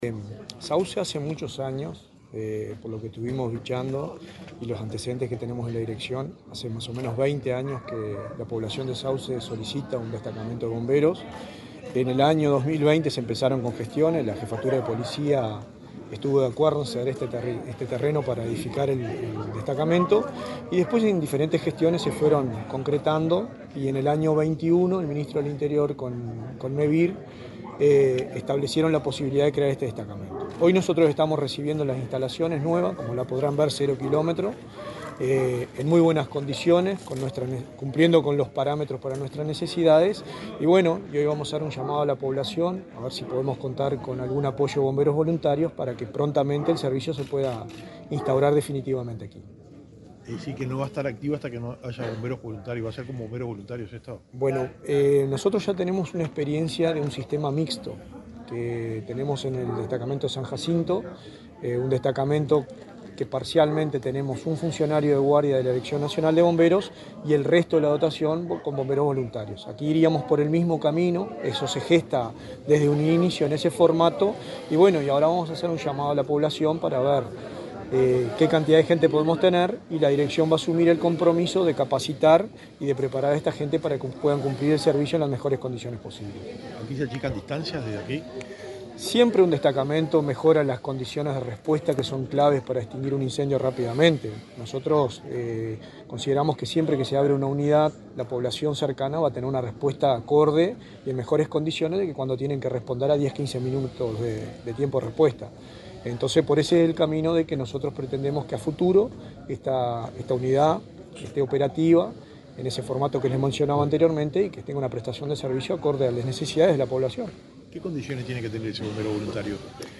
Declaraciones del director nacional de Bomberos, Richard Barboza
Declaraciones del director nacional de Bomberos, Richard Barboza 29/04/2024 Compartir Facebook X Copiar enlace WhatsApp LinkedIn El Ministerio del Interior y Mevir realizaron, este lunes 29 en Canelones, el acto de habilitación del cuartelillo de Bomberos construido en la localidad de Sauce. Antes, el titular de la Dirección Nacional de Bomberos, Richard Barboza, dialogó con la prensa acerca del funcionamiento del nuevo local.